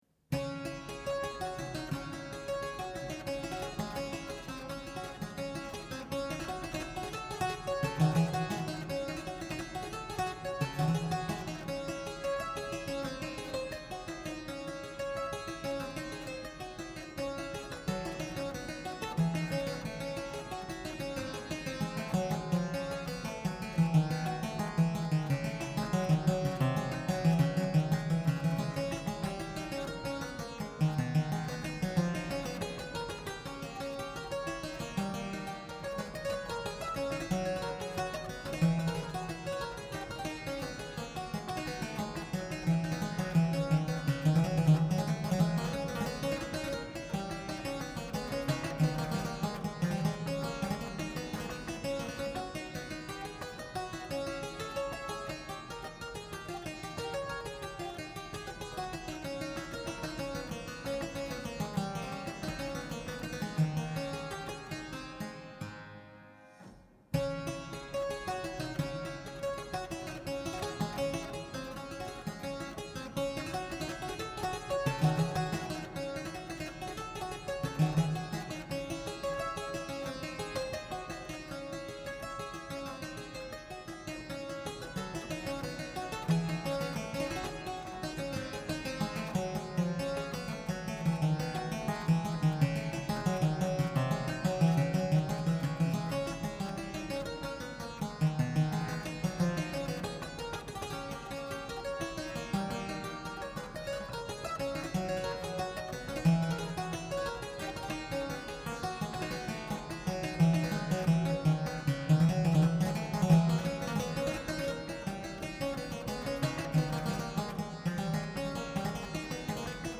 harpsichord and clavichord